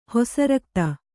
♪ hosa rakta